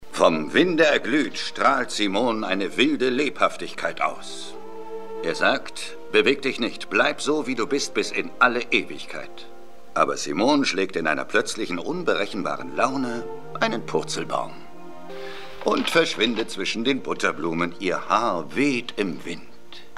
Hörprobe des deutschen Synchronschauspielers (312 Kb)